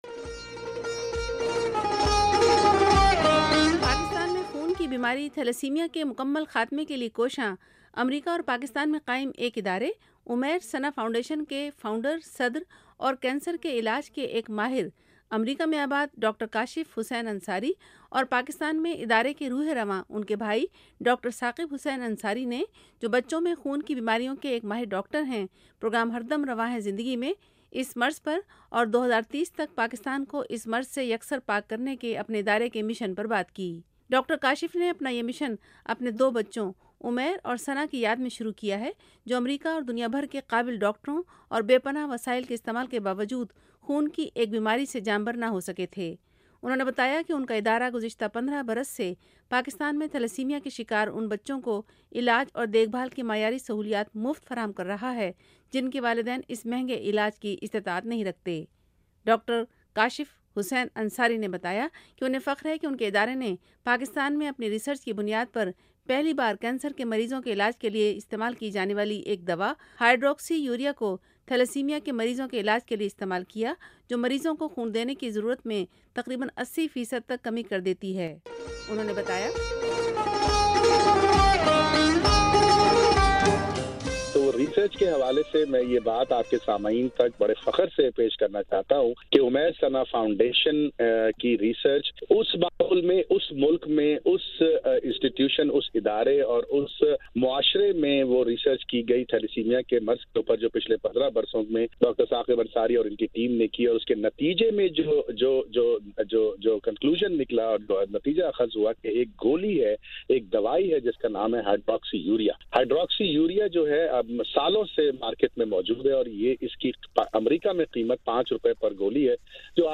انٹرویو